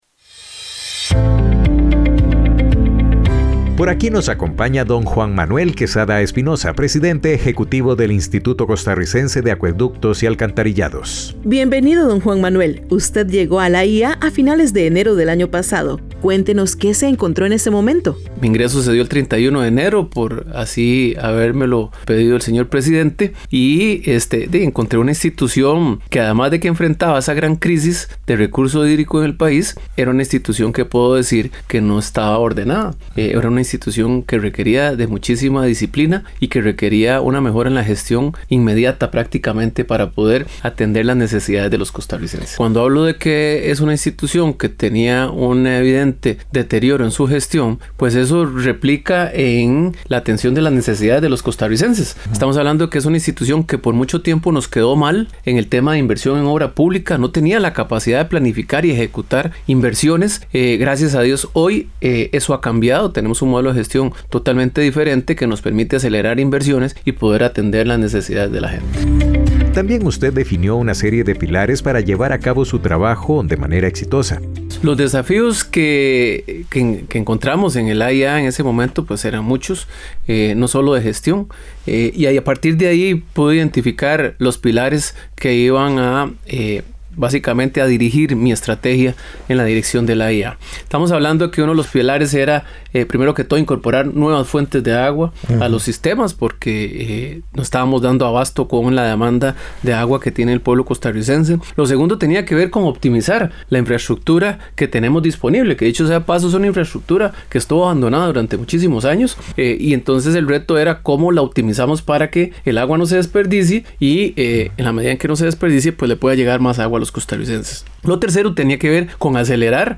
Entrevista a Juan Manuel Quesada Espinoza, presidente Ejecutivo del AyA